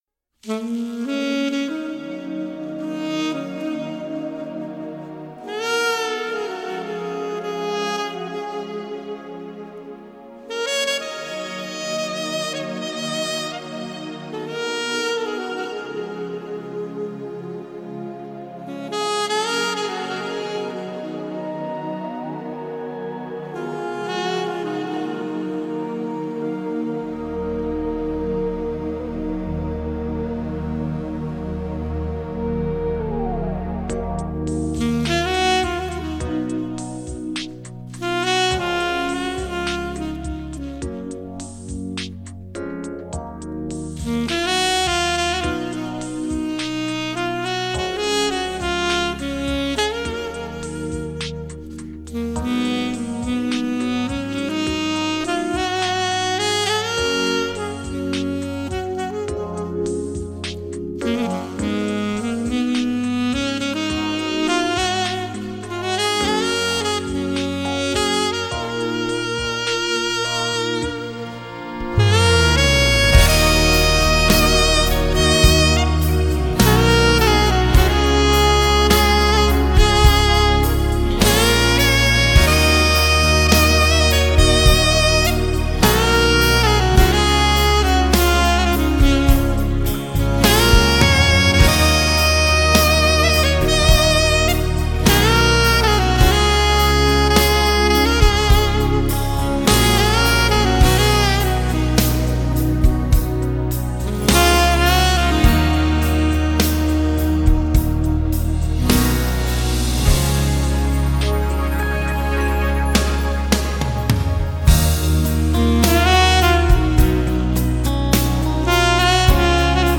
Саксофон